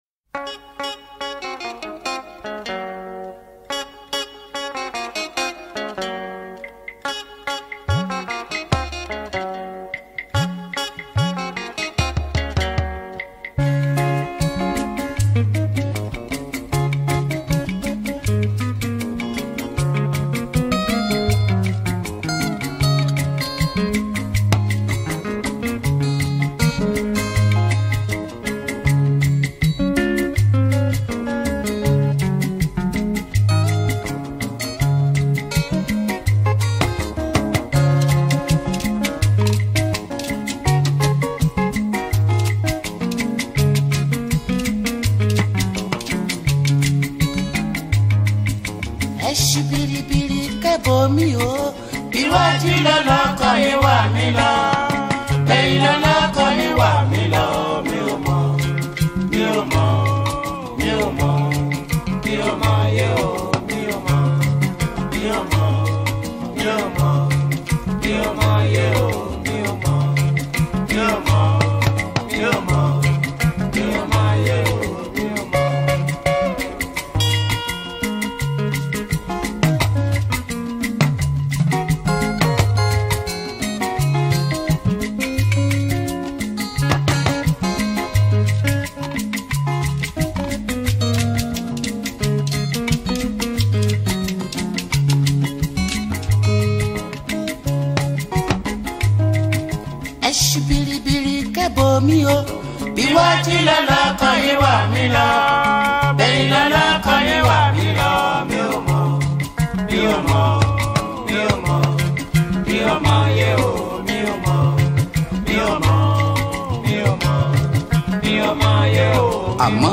February 14, 2025 Publisher 01 Gospel 0